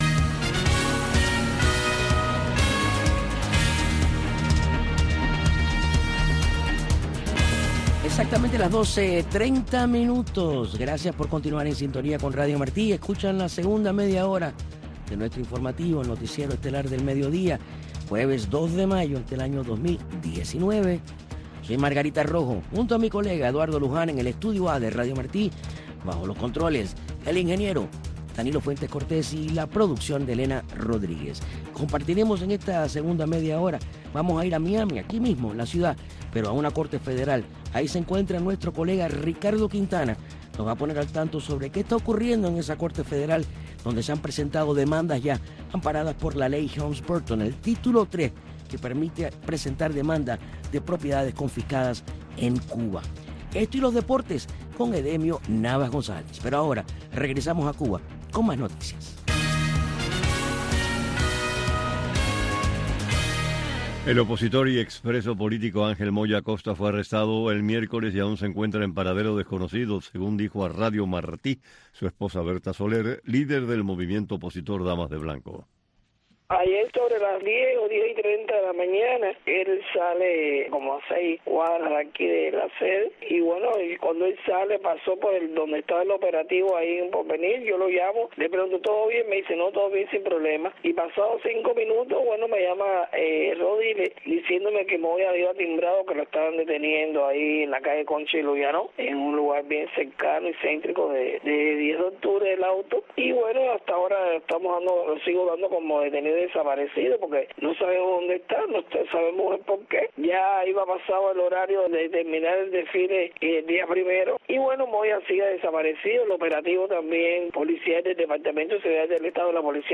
Más de 50 entrevistas, testimonios, recuentos y anécdotas de laicos, religiosas y sacerdotes que repasan la historia de la Arquidiócesis de Miami y la huella de los exiliados cubanos en su crecimiento.